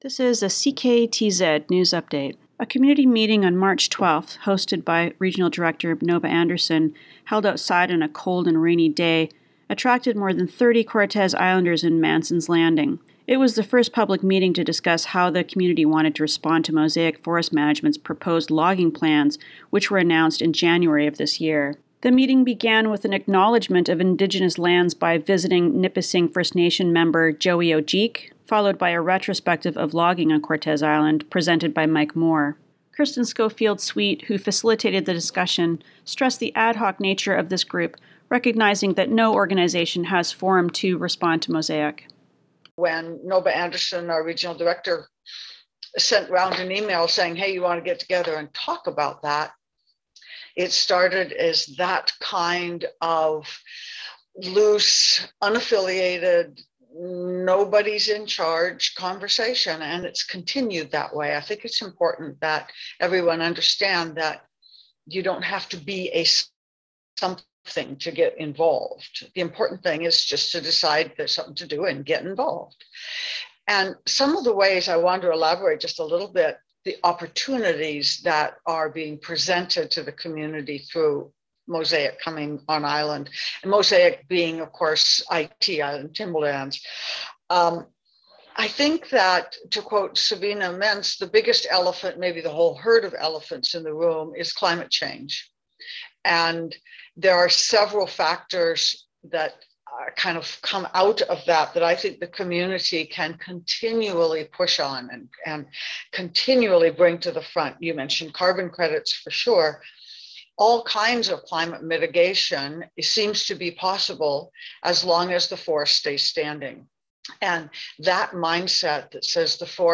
In response to Mosaic Forest Management's proposed logging activity on Cortes Island, announced in January, a public meeting was held outdoors at the Village Commons lot in Mansons Landing on March 12. More than 30 people attended on the cold and rainy day under a tent set up for the event.